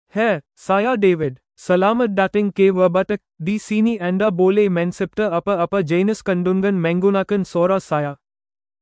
DavidMale Malayalam AI voice
David is a male AI voice for Malayalam (India).
Voice sample
Male
David delivers clear pronunciation with authentic India Malayalam intonation, making your content sound professionally produced.